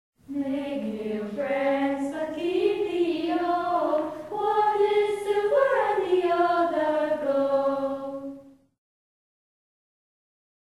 Campfire Songs